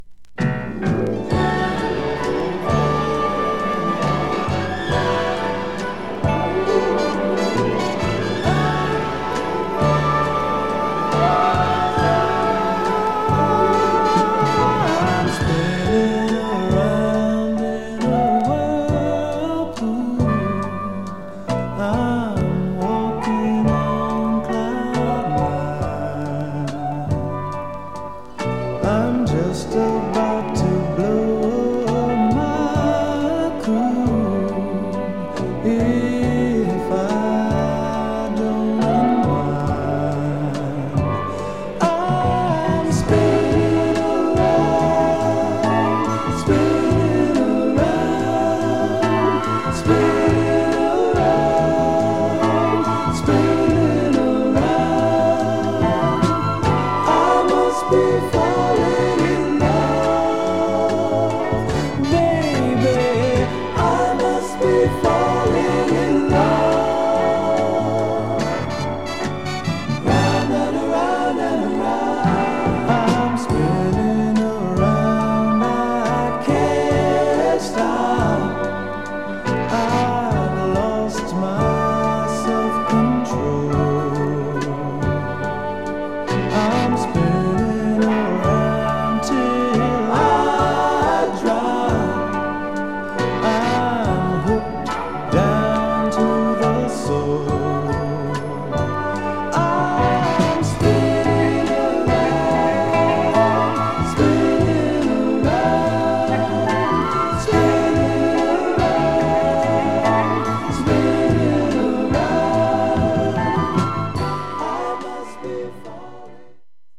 R&B�ҥåȤ�������������������ȥ����롣�ץ����ס�